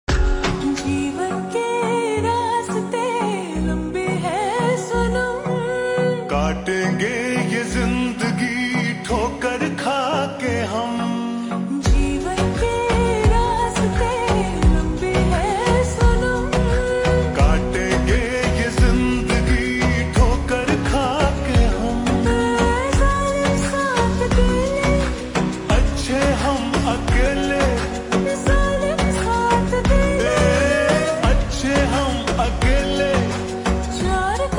Hindi Songs
(Slowed + Reverb)